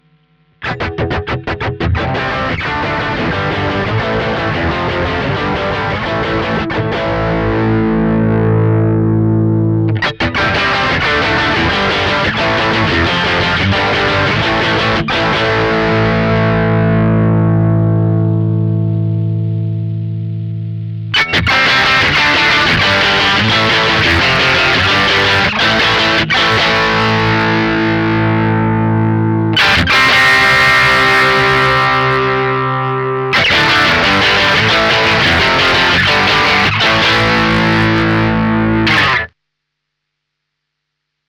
Rock Riff Tone Variation
Variation on the fly via Sennheiser MD 421
vox_mv50_rock_test__rockriff_tone_var.mp3